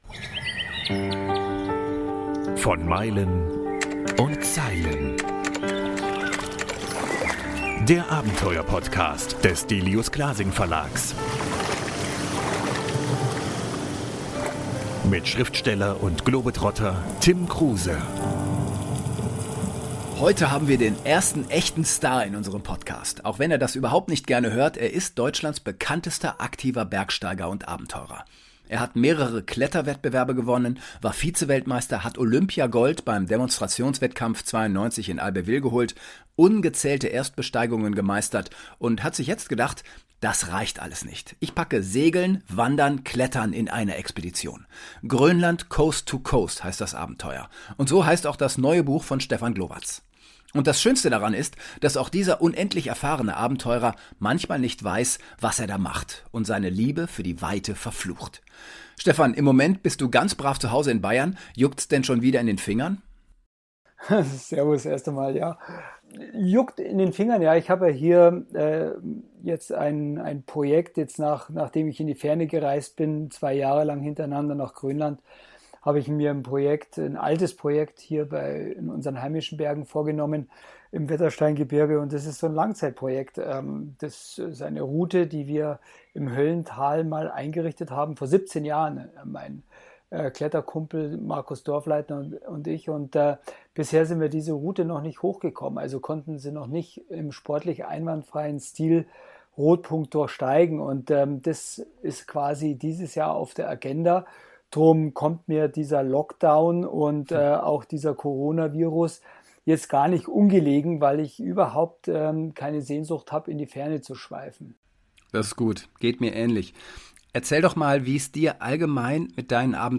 Abenteurer und Kletterer Stefan Glowacz ist der heutige Gast bei "Meilen und Zeilen".